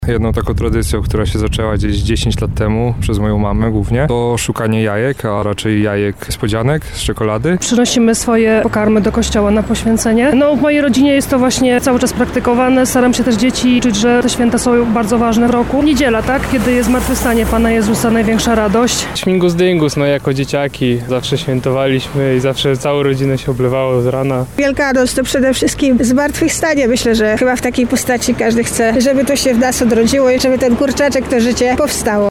Zapytaliśmy mieszkańców naszego miasta, jakie tradycje goszczą w ich domach w tym czasie:
Sonda – tradycje wielkanocne